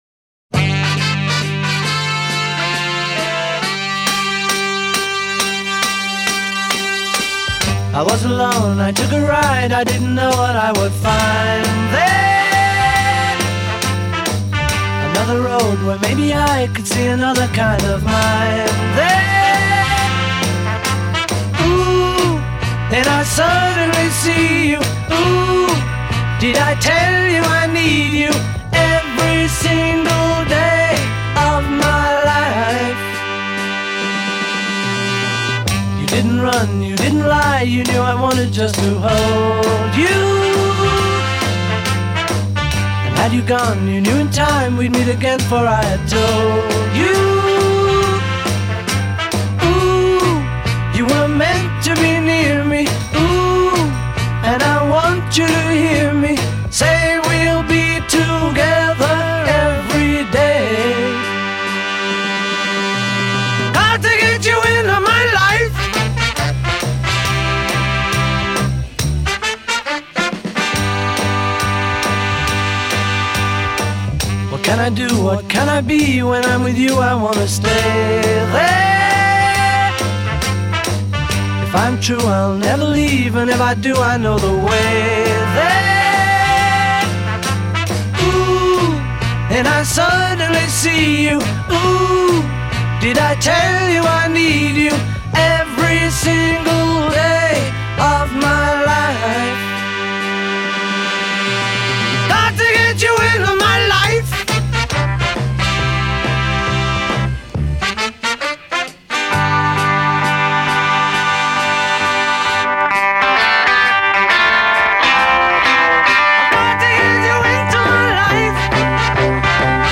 electric guitar-rock sound